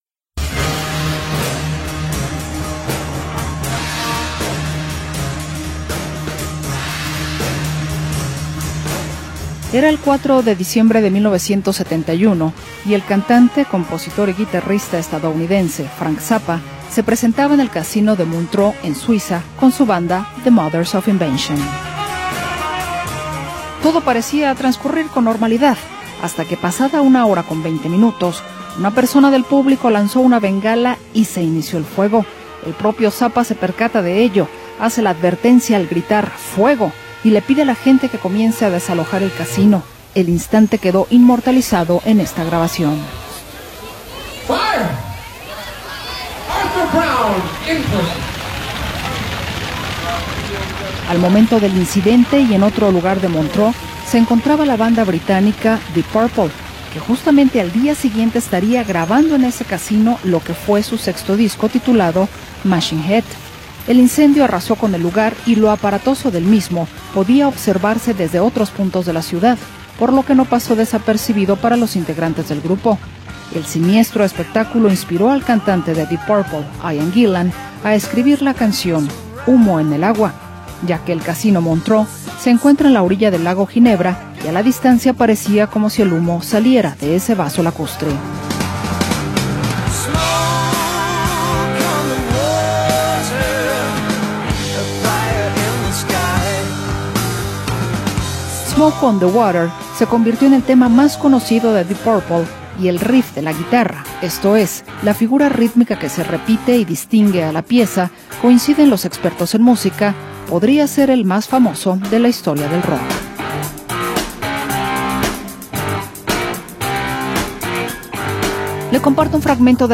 banda británica de rock
Reconocida por su legendario riff de guitarra